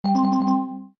003 Zumbido Messenger
003-Zumbido-messenger.mp3